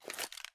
change_weapon.ogg